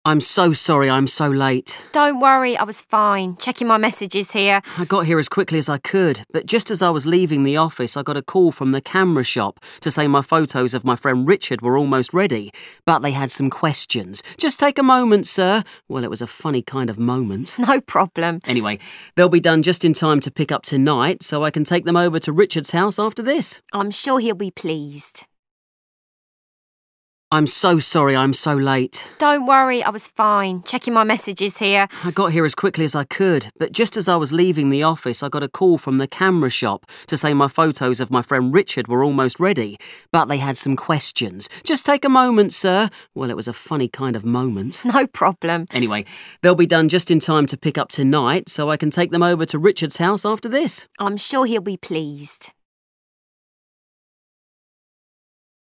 5. You overhear two people talking in a café. Where has the man just come from?